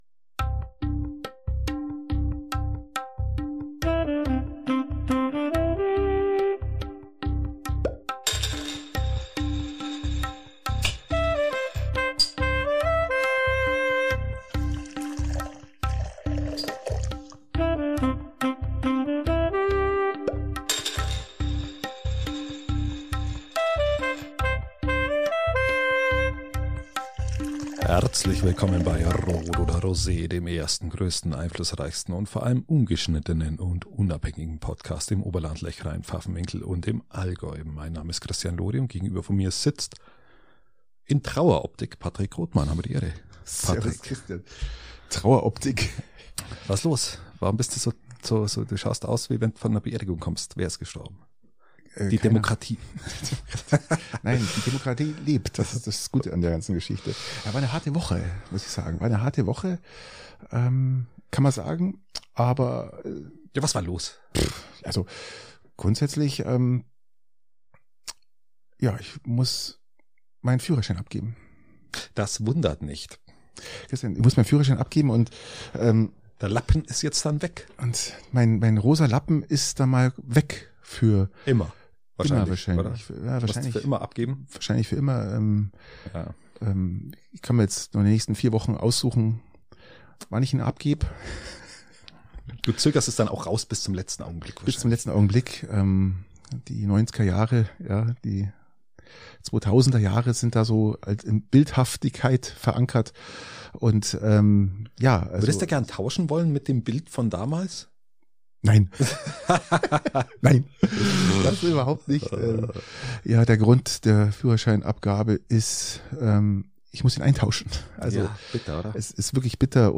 Ungeschnittenen und unabhängig!